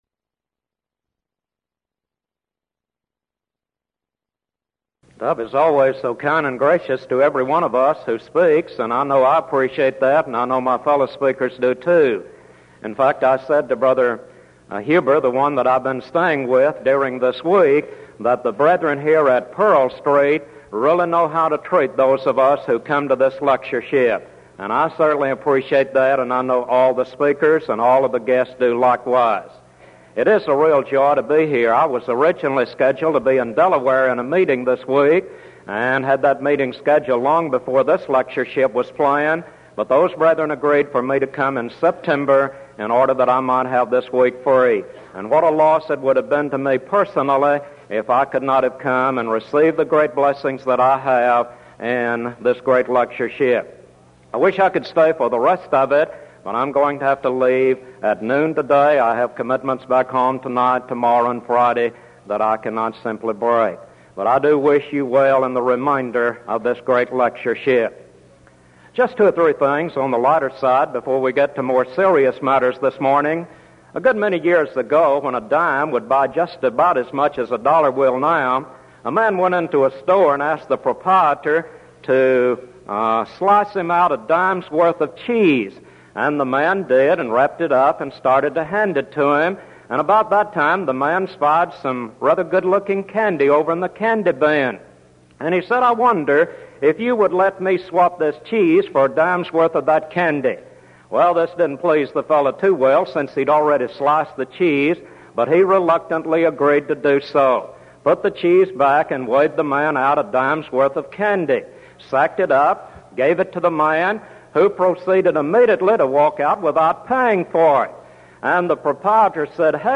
Event: 1984 Denton Lectures Theme/Title: Studies in the Book of Revelation
lecture